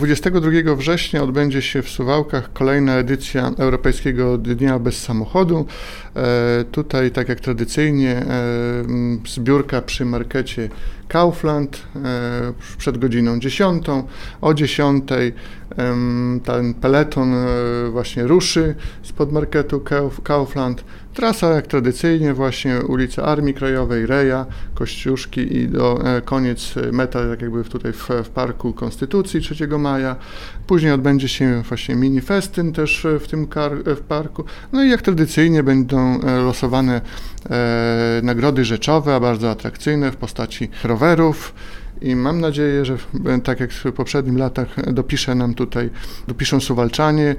O szczegółach Łukasz Kurzyna, zastępca prezydenta Suwałk.